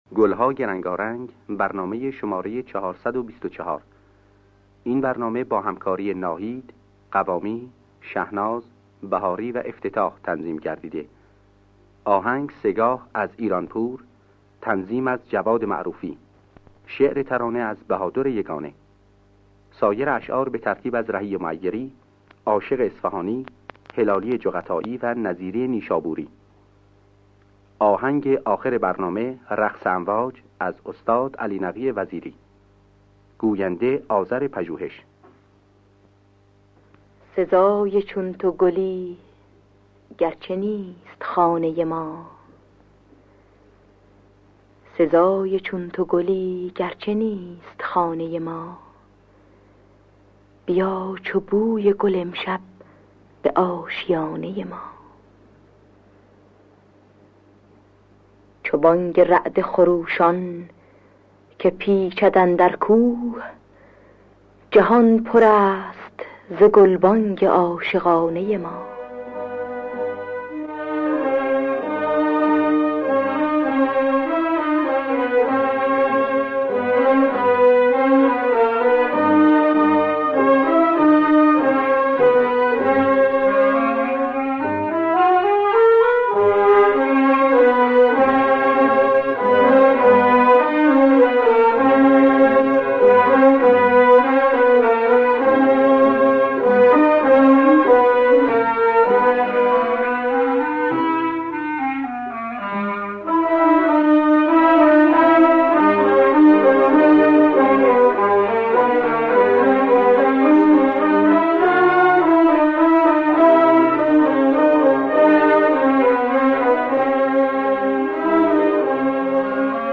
گلهای رنگارنگ ۴۲۴ - سه‌گاه
نوازندگان: اصغر بهاری جلیل شهناز جواد معروفی